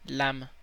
Ääntäminen
Synonyymit carte à jouer ciseau Ääntäminen Canada (Montréal) - lame Tuntematon aksentti: IPA: /lam/ Haettu sana löytyi näillä lähdekielillä: ranska Käännös Konteksti Ääninäyte Substantiivit 1. sheet UK UK US 2. foil US 3.